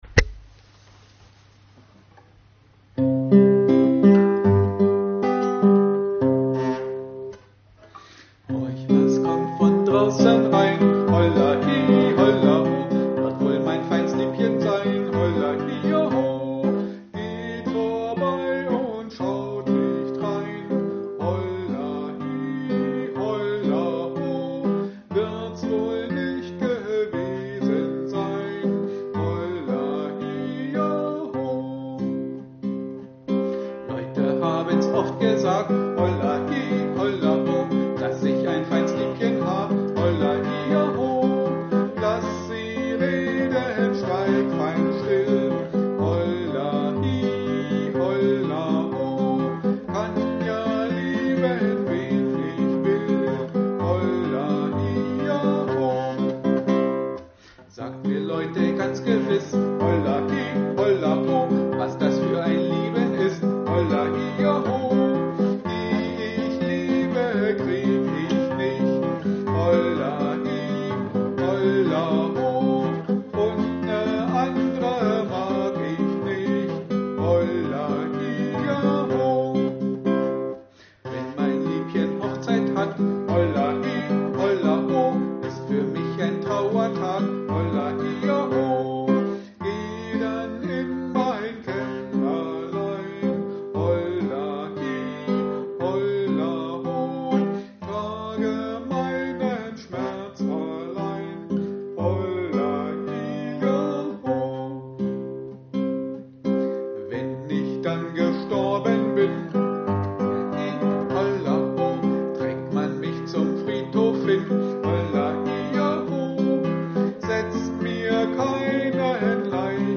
Da auch wir zur Zeit in vielen Seniorenheimen nicht arbeiten können und folglich auch keine Gruppen stattfinden, haben wir Ihnen ein kleines Liederbuch mit dazugehöriger Begleitung zusammengestellt.